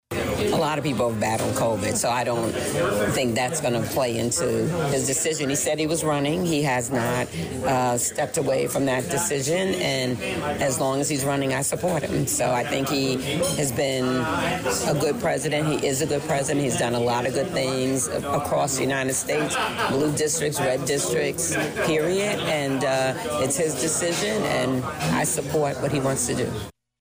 During their Thursday (July 18th) afternoon stop at the Gilbert Street Cafe for a roundtable on rural issues, Democratic State Senator Paul Faraci and Democratic Congresswoman Robin Kelly were asked by Central Illinois Media Group about the current national political situation for their party.